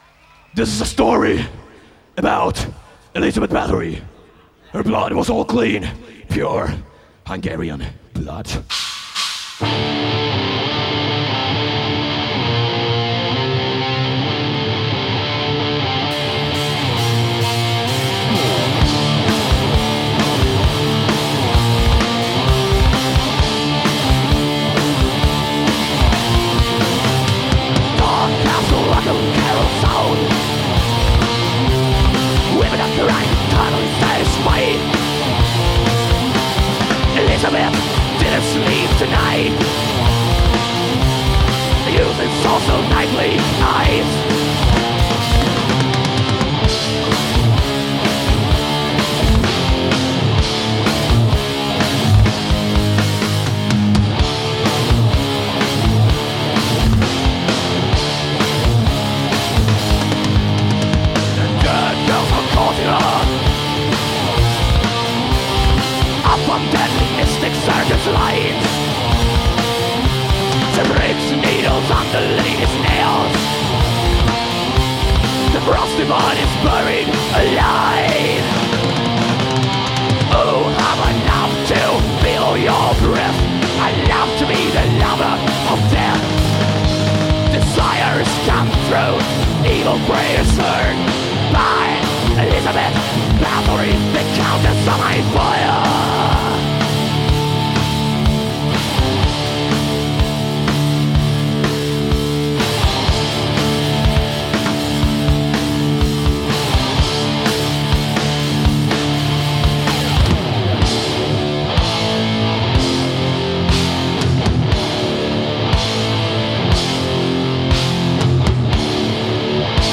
Live
Metal